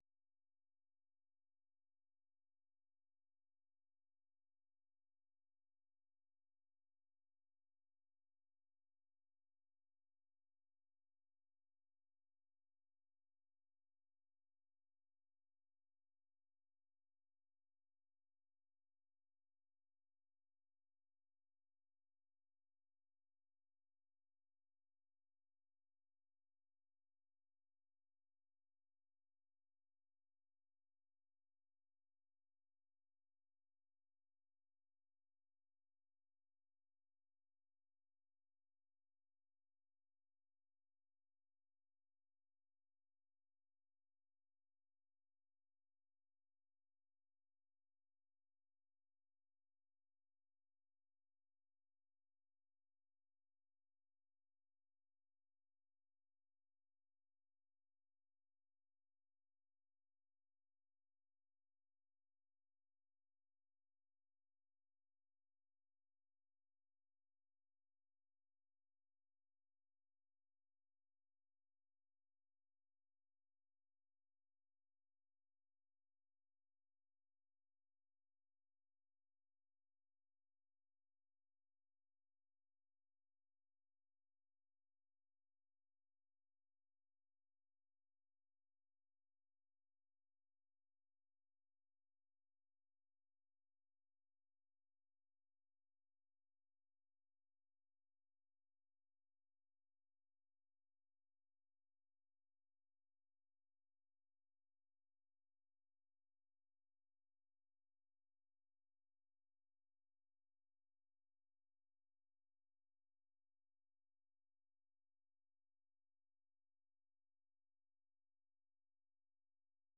Soul USA - un retour dans les endroits mythiques de la Soul des années 60 et 70.